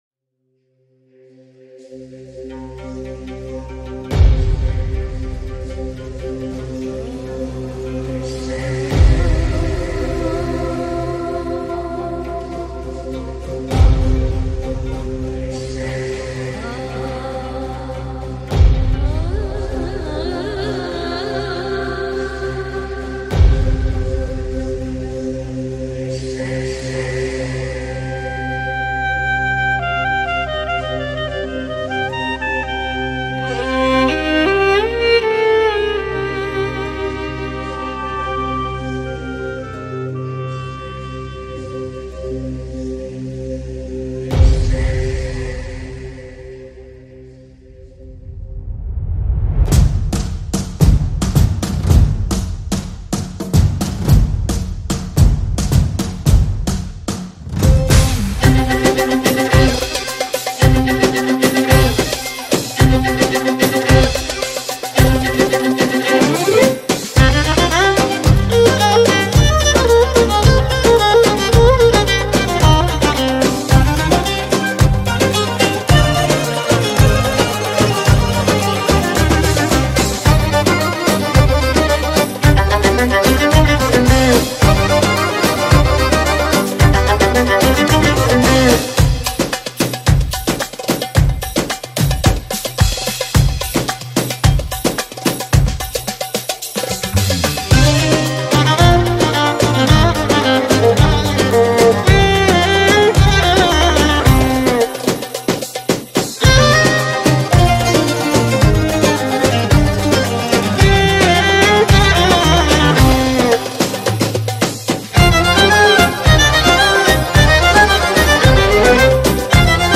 Carpeta: musica arabe mp3
Violin and Dance show